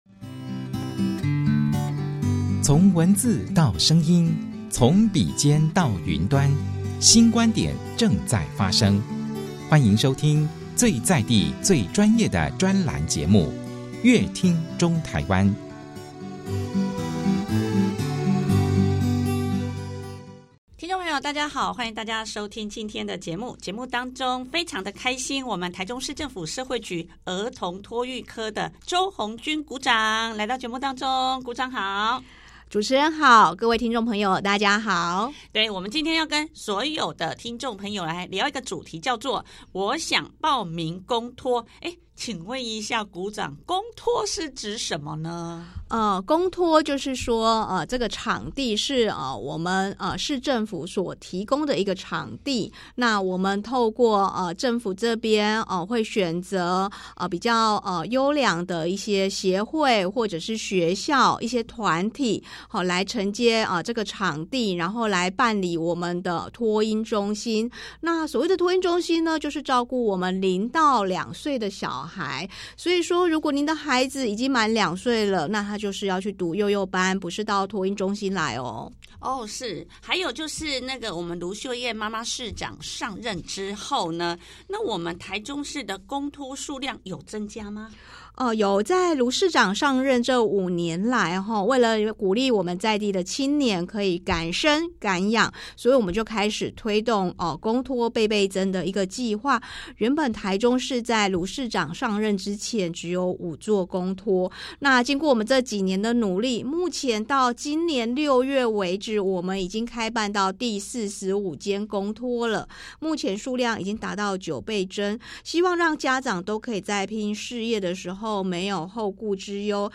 想知道更多精彩的專訪內容，歡迎大家鎖定本集節目收聽。